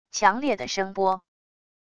强烈的声波wav音频